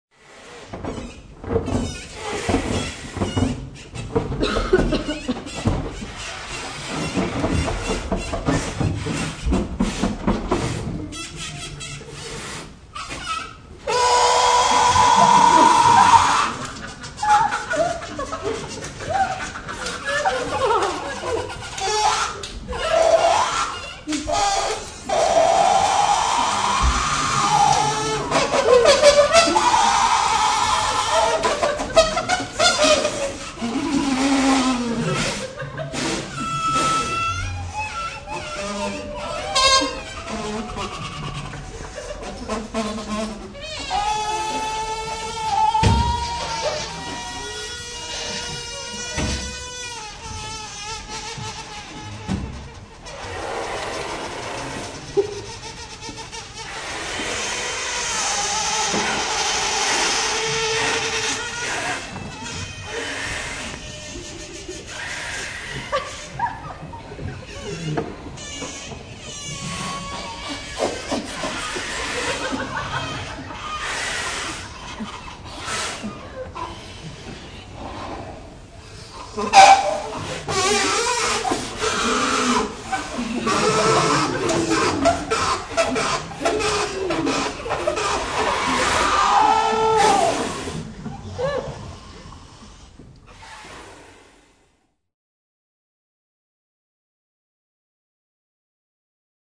Schilleroper Hamburg 15.April 2002